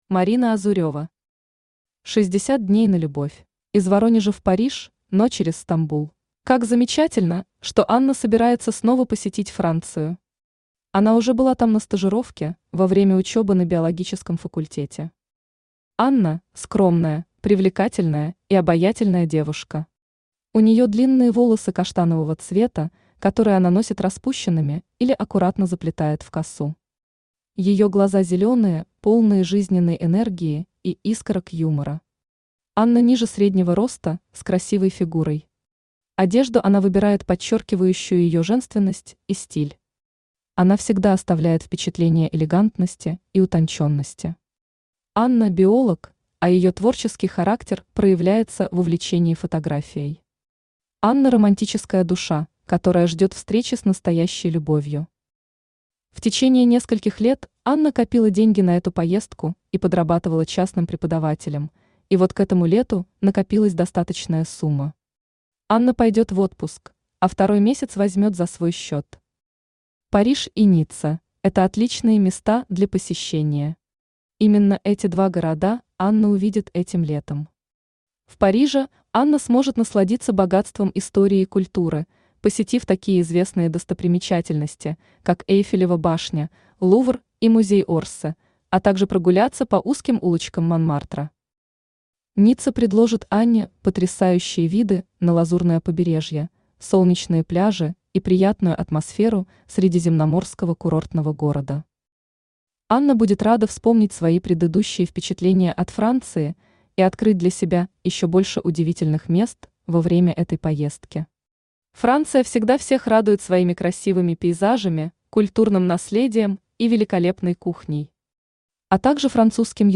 Аудиокнига 60 дней на любовь | Библиотека аудиокниг
Aудиокнига 60 дней на любовь Автор Марина Азурева Читает аудиокнигу Авточтец ЛитРес.